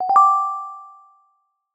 chime.ogg